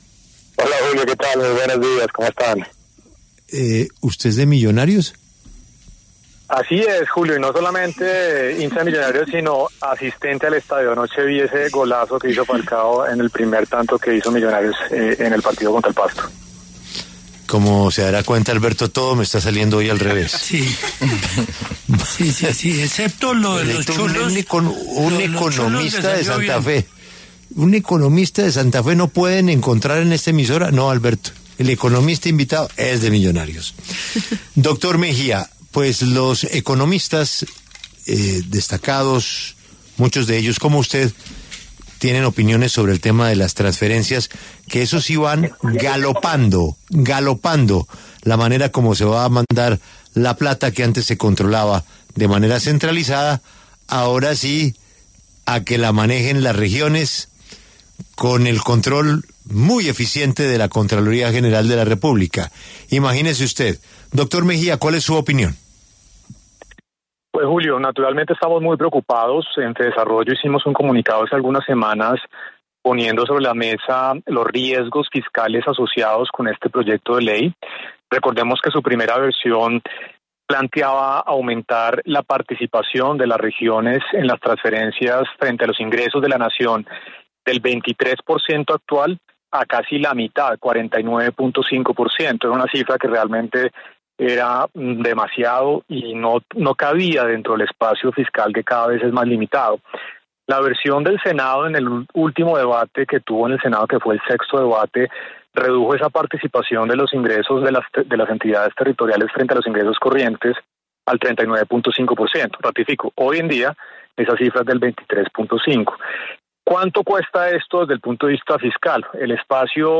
Naturalmente, estamos muy preocupados”, afirmó en los micrófonos de La W, pues “generaría un gasto adicional cercano al 2% del Producto Interno Bruto, equivalente a cerca de 33 billones de pesos anuales”.